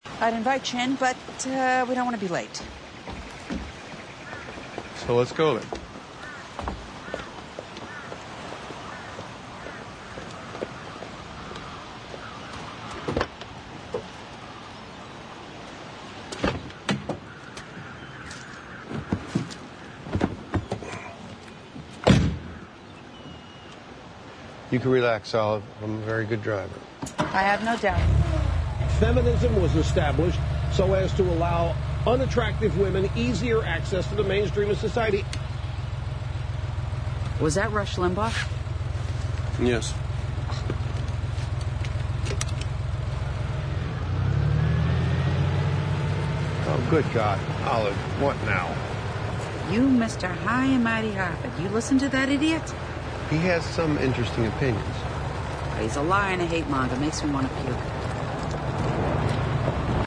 It was actress Frances McDormand in the second half of the pay network’s two-night Olive Kitteridge mini-series about the later years of life of a woman living in a coastal Maine town.
Her disgust with Limbaugh bursts out when she gets into a car with another widower, played by Bill Muray, who is taking her to dinner. When he starts his car (she earlier showed disdain for his vanity in owning a convertible sports car), Limbaugh’s voice is on the radio just happening to say this very famous line: “Feminism was established so as to allow unattractive women easier access to the mainstream of society.”
The Murray character turns the radio off, but “Olive Kitteridge” demands: “Was that Rush Limbaugh?”